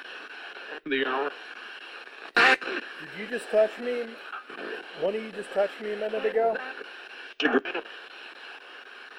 Here is an enhanced audio from the closet:
We did pick up some unusual sounds on the recorder